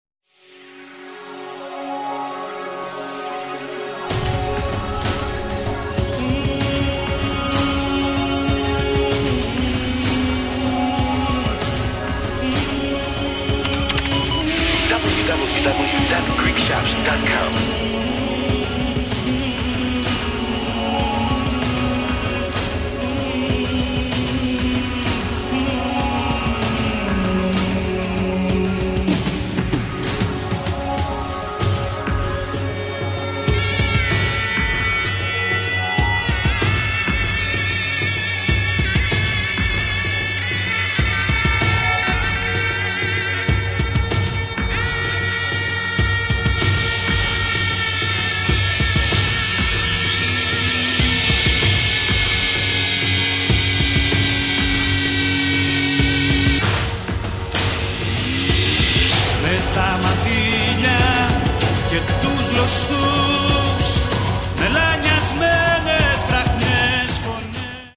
all of best hits by the top-selling light rock duo